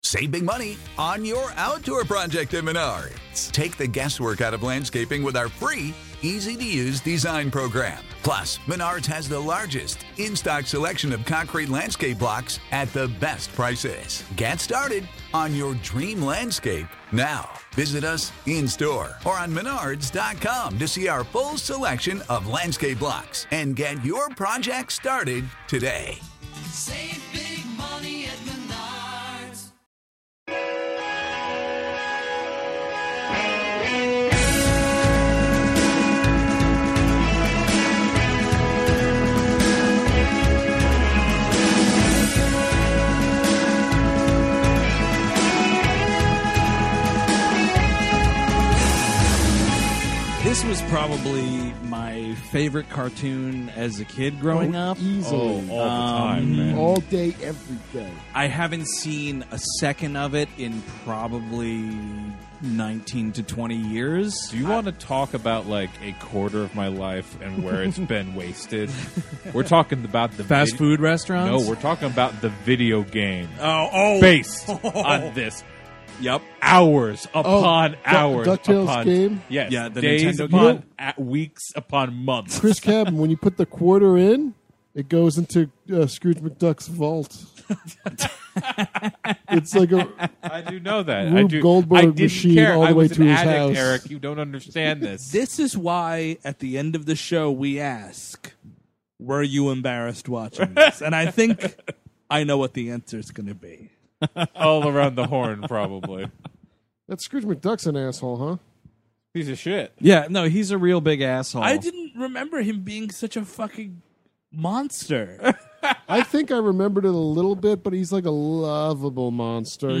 PLUS: The guys all try on Huey, Dewey, and Louie impressions!